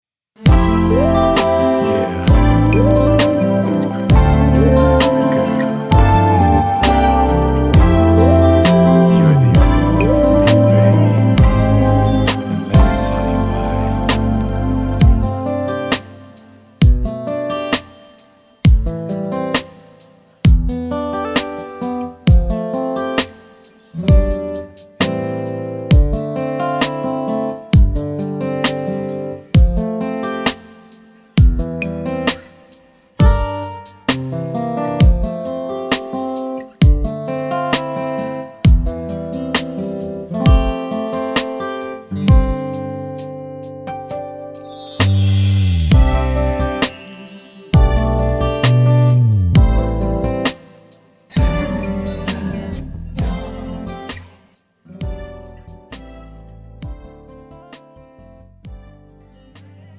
음정 코러스
장르 축가 구분 Pro MR